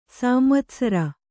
♪ sāmvatsara